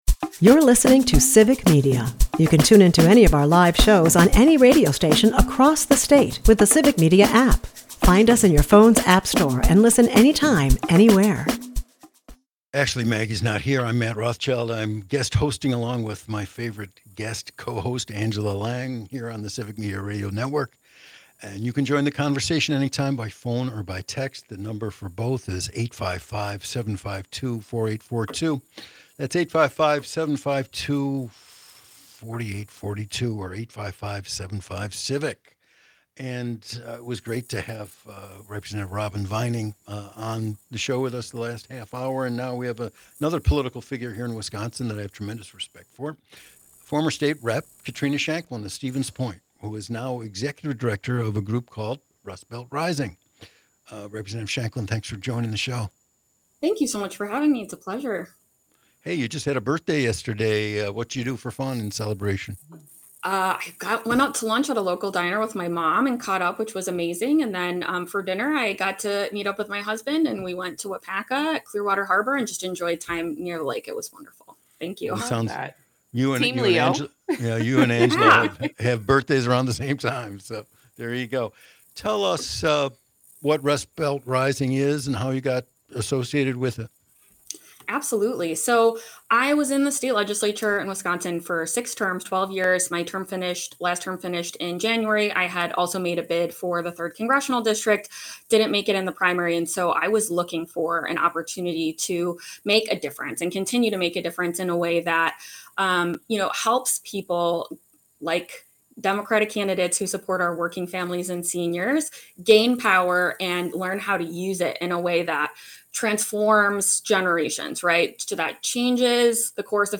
Guests: Katrina Shankland